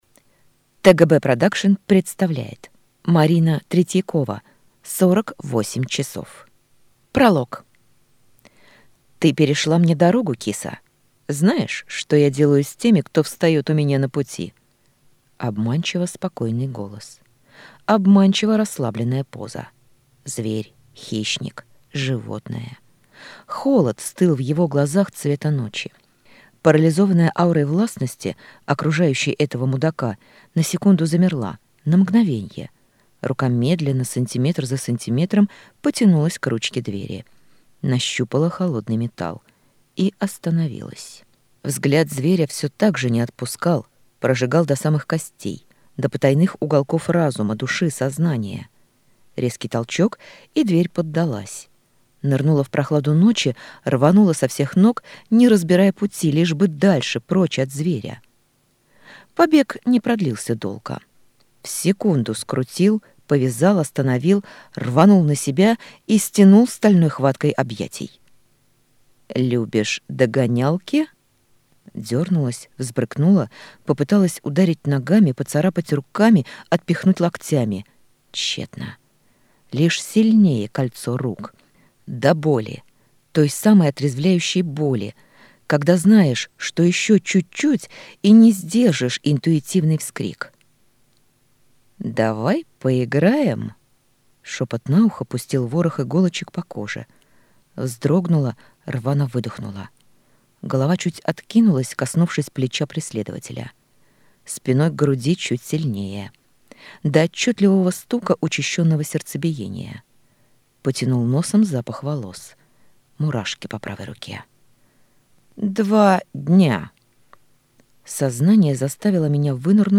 Аудиокнига 48 часов | Библиотека аудиокниг